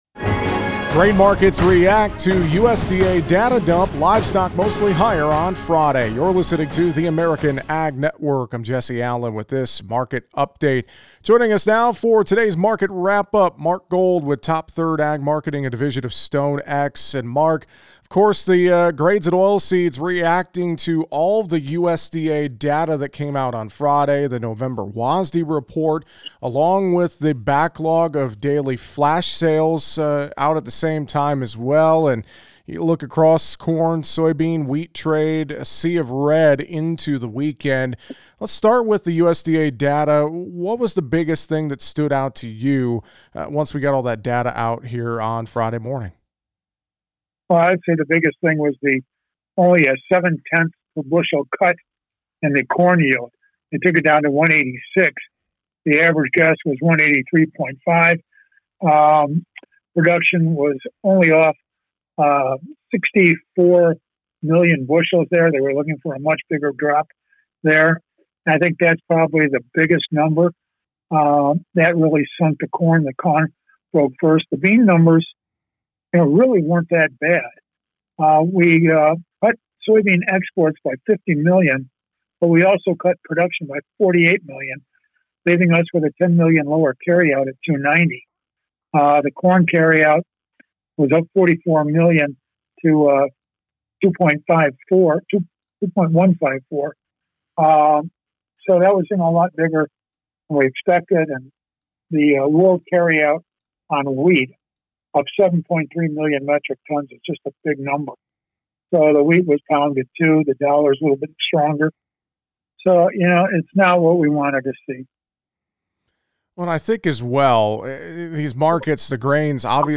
joins us for a conversation and reaction on the Friday Market Wrap-Up below: